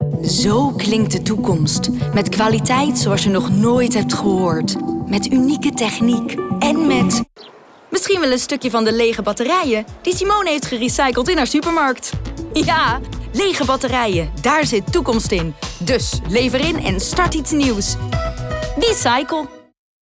Wecycle-batterij-radiocommercial-2025
Beluister hier de radiocommercial over batterijen van Wecycle 'Daar zit toekomst in'.